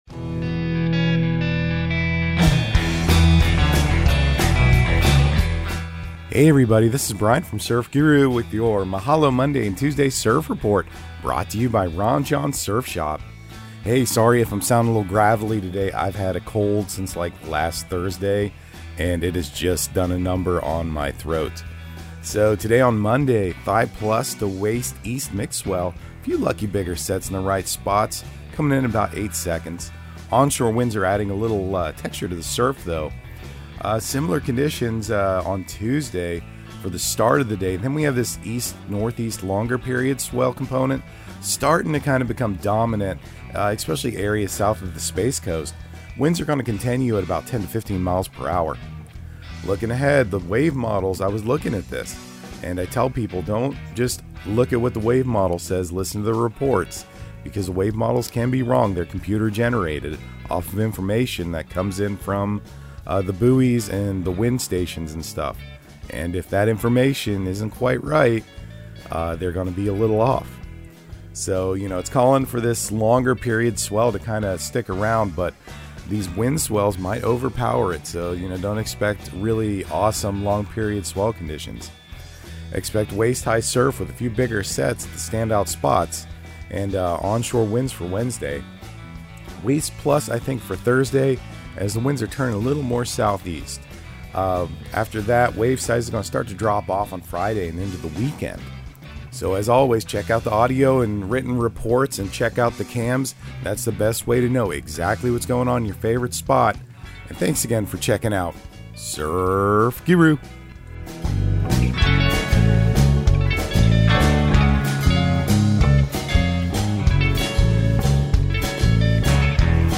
Surf Guru Surf Report and Forecast 04/24/2023 Audio surf report and surf forecast on April 24 for Central Florida and the Southeast.